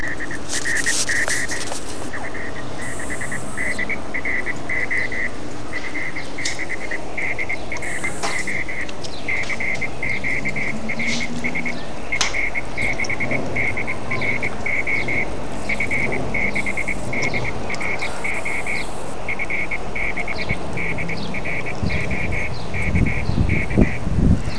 NB : Désolé pour la piètre qualité de ces enregistrements de cet article qui ont été effectués avec mon smartphone « collé » à l’oreillette du casque. On entend ainsi parfois les bruits de la rue en contrebas !